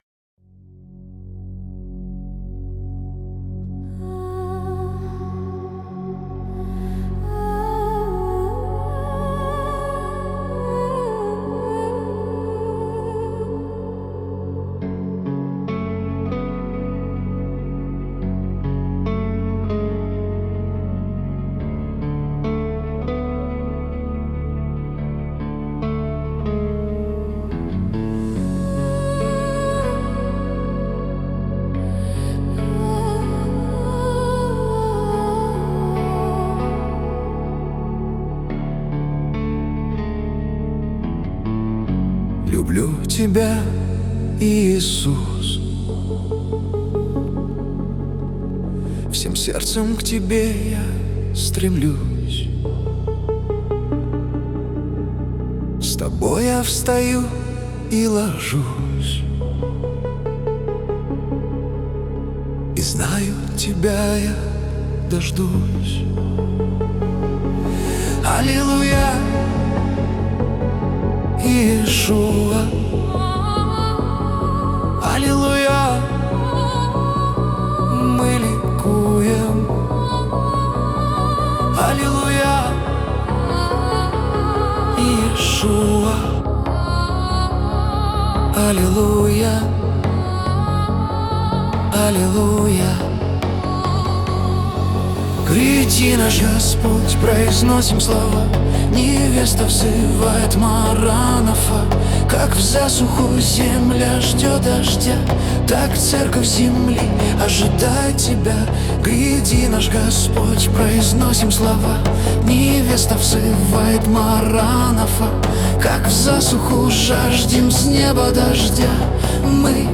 песня ai
96 просмотров 512 прослушиваний 46 скачиваний BPM: 71